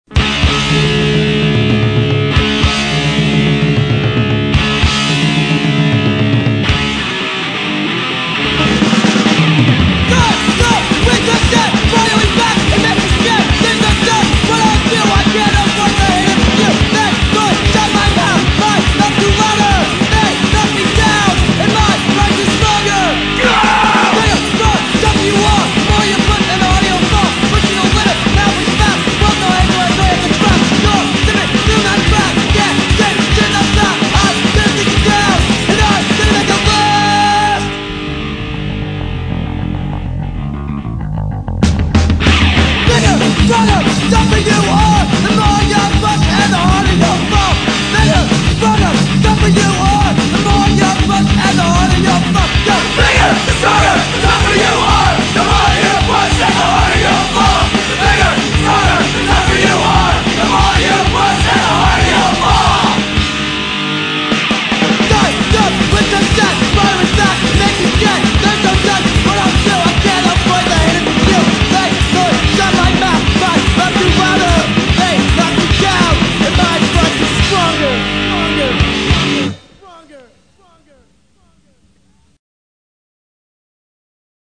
hardcore band
cassette
Punk Rock Music